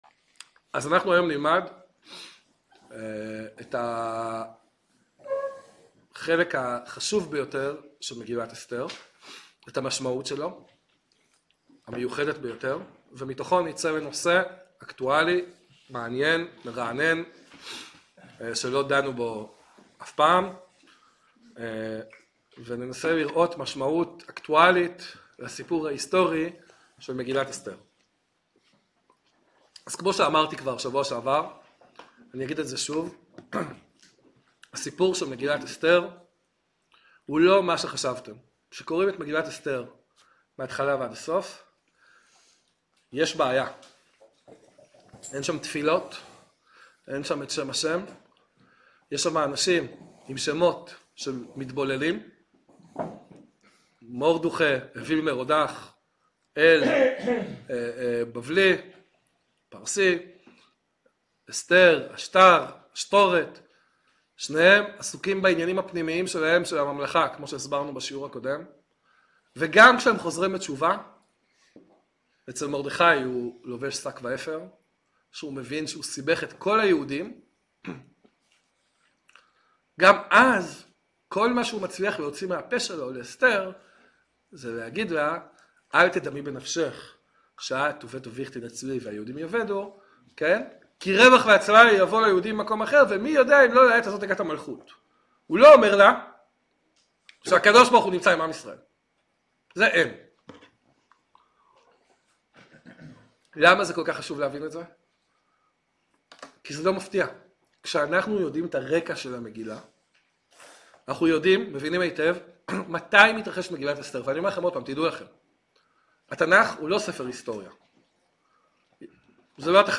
שיעור עמוק ומאיר עיניים המקשר בין הסיפור התנ"כי המופלא ליחס בימינו בין יהדות מדינת ישראל לאחינו יהודי ארצות הברית.